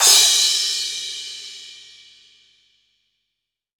• Crash Sample B Key 07.wav
Royality free crash cymbal single shot tuned to the B note. Loudest frequency: 5559Hz
crash-sample-b-key-07-Zzq.wav